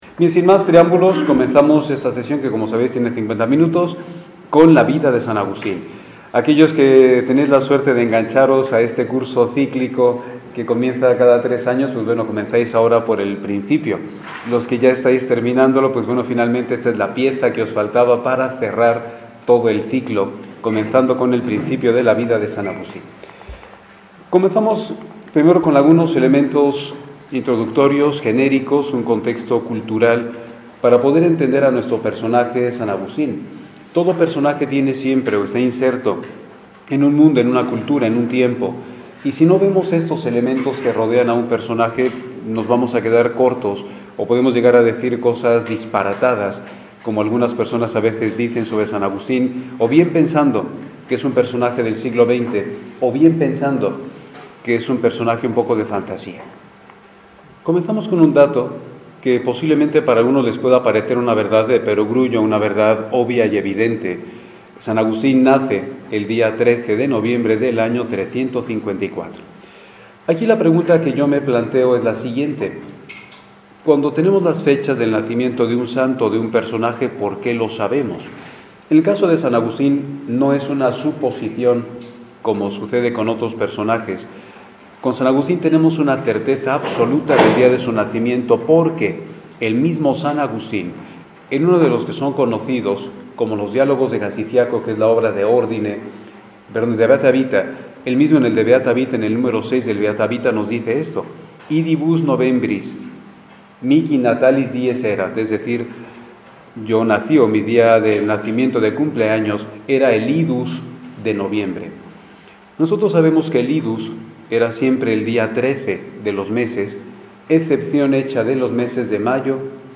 en el curso de Formación Propia